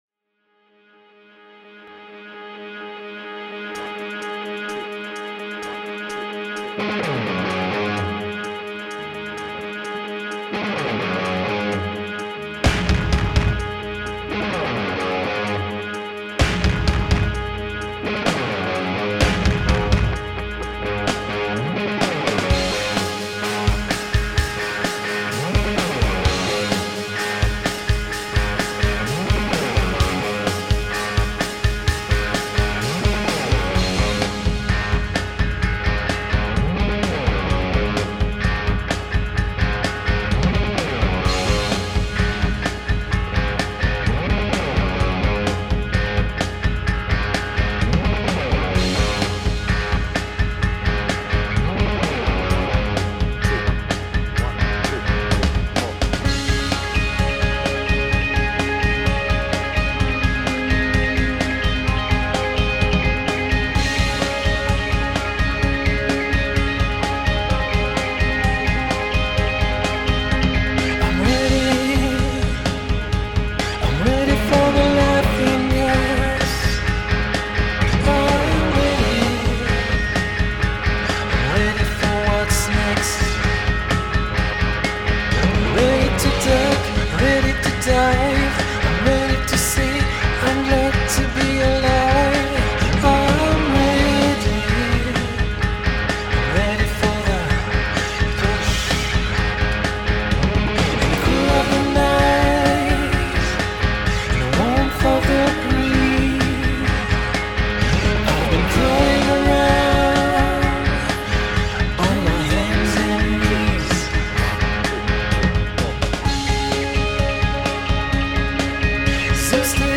BPM : 128
Tuning : Eb
Without vocals